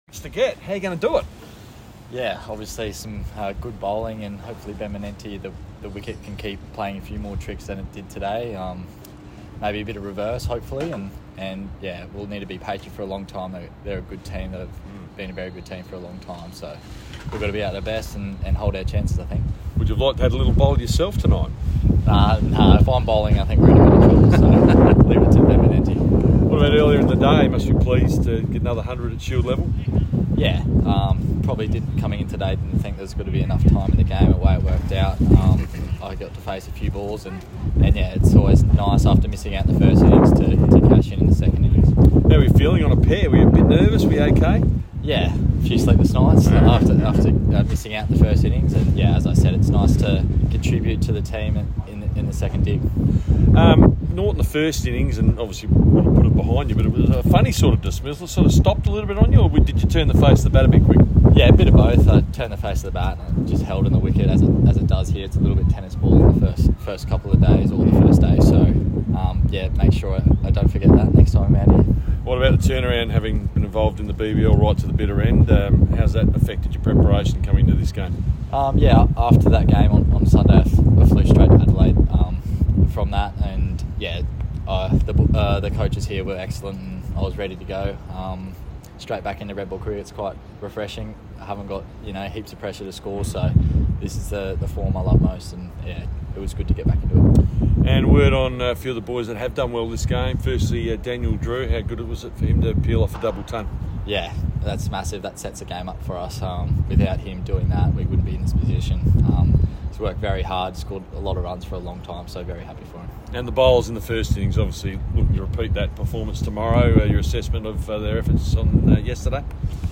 SA’s Nathan NcSweeney spoke the media after Day Three of the Shield clash at Adelaide Oval